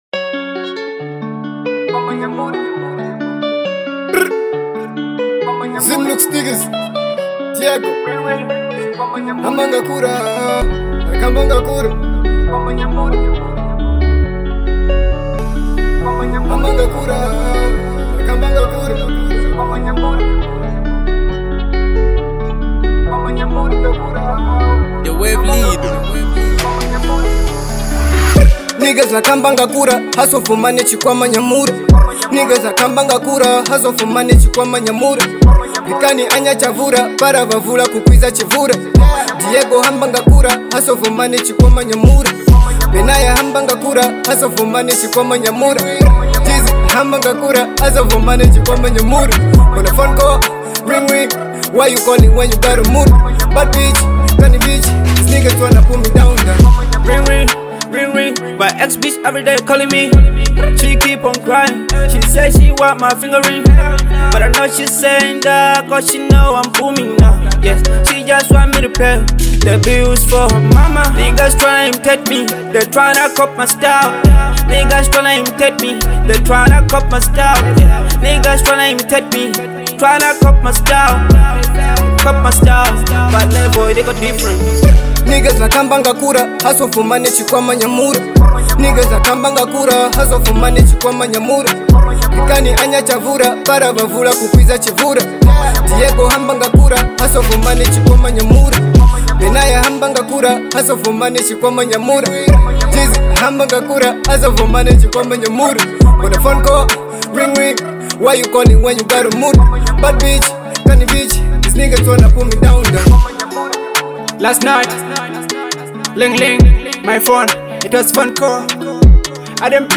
trap single
electrifying sound